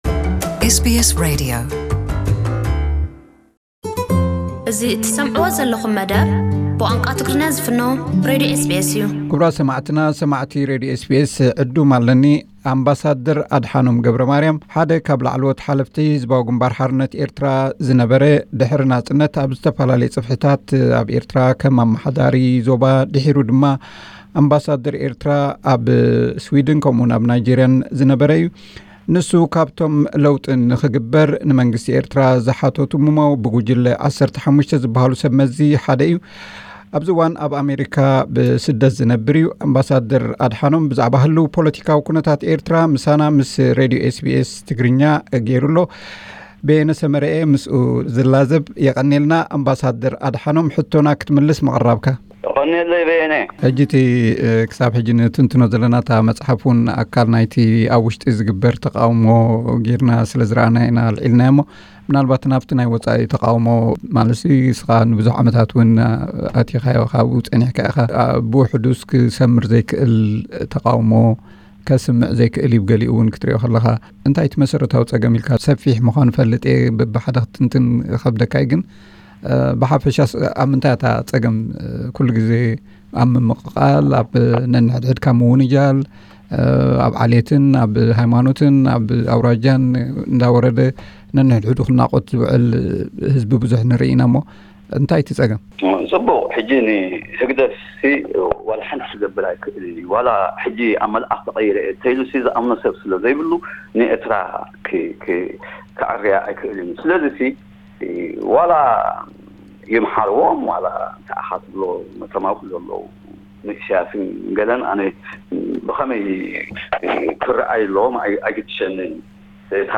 ኣብዚ ካልኣይ ክፋል ቃለ መሕትት ካብ ዝተላዕሉ ነጥቢታት፡ ጀበርቲ መንደፈራ፡ ኣግኣዝያን፡ ጀሃድ፡ ኣብ ዞባ ደቡብ ንእስላም ንምክትታል ዝተገብረ ፈተናን ውጽኢቱን፡ ሓጂ ሙሳ፡ ጉጅለ ወዲ ዓሊን ፈተነ ፎርቶን ካልእን።